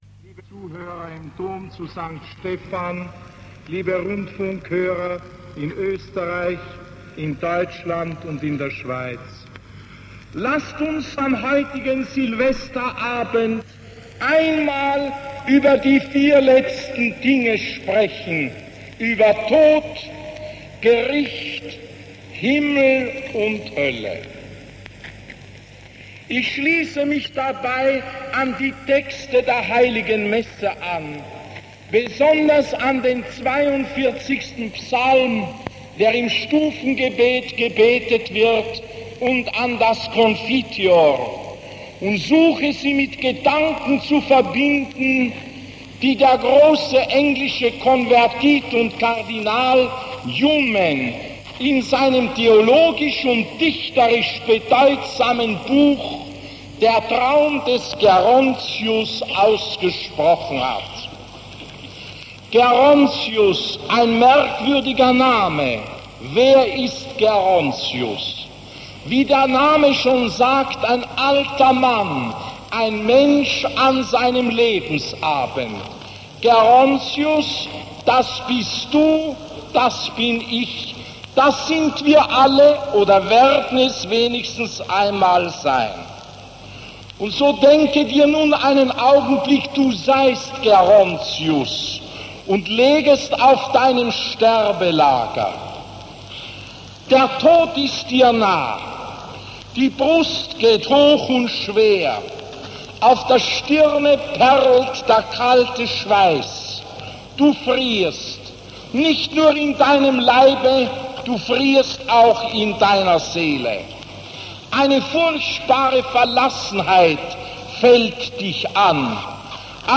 Die Predigt datiert aus dem Jahr 1964 und sucht an Wortwahl und Sprachgewalt ihresgleichen.
silvesterpredigt(1).mp3